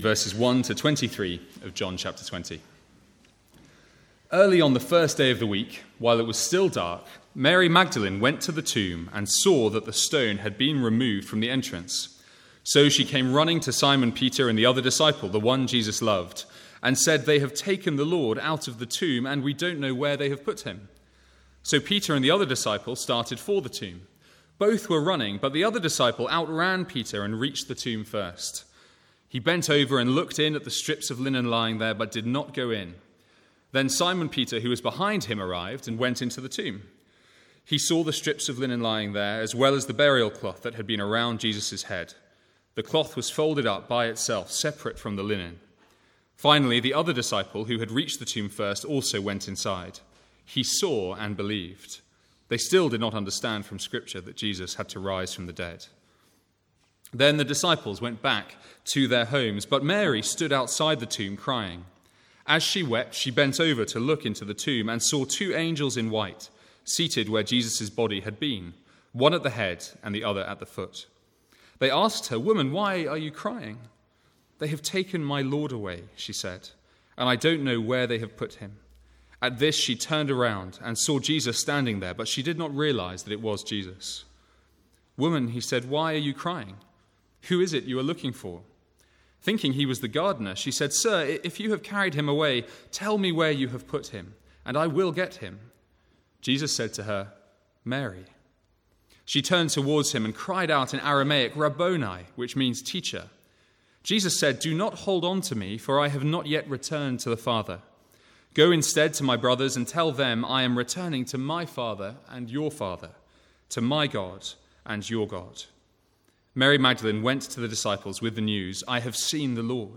From the morning service on Easter Sunday 2015.
Sermon Notes